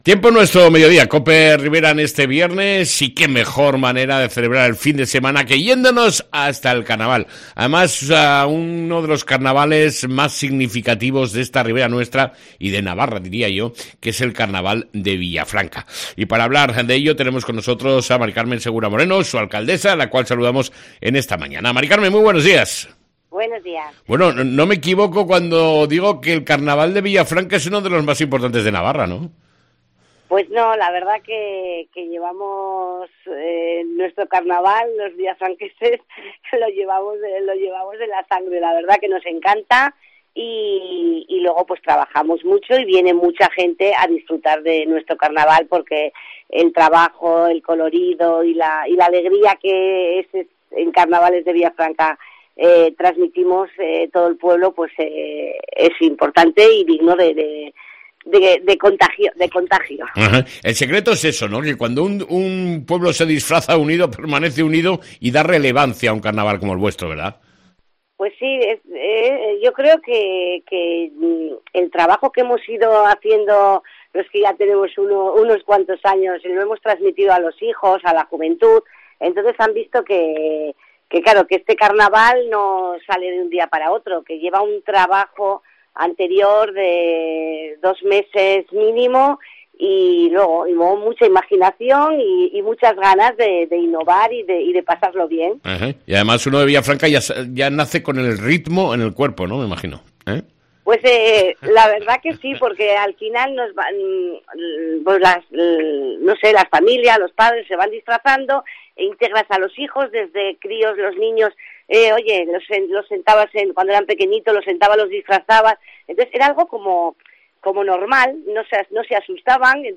ENTREVISTA CON LA ALCALDESA DE VILLAFRANCA, Mª CARMEN SEGURA